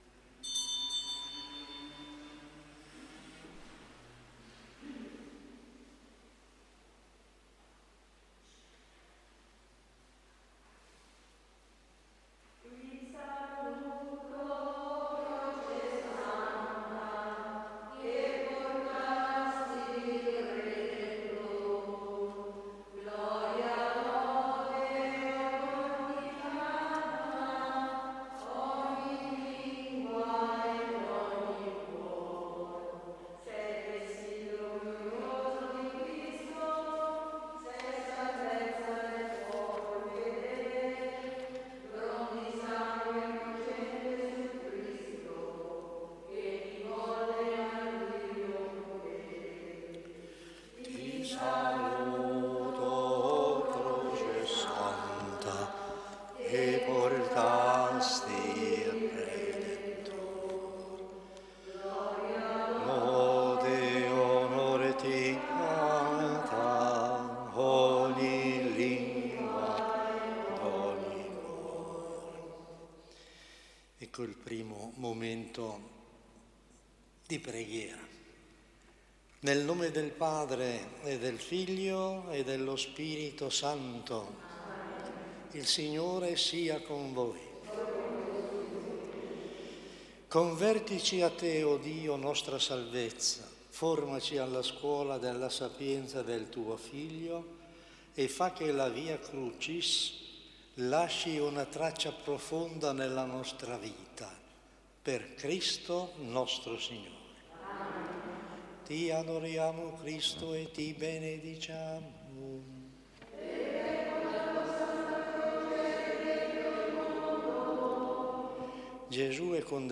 Prima Meditazione